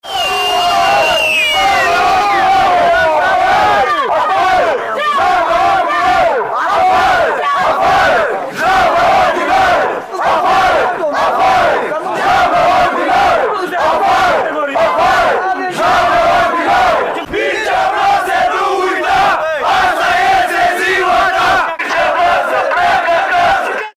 150 de oameni au protestat, noaptea trecută, la Pungești, imediat după numărarea voturilor, fiind nemulțumiți că a fost fraudată consultarea populară:
21-mar-rdj-8-protest-pungesti.mp3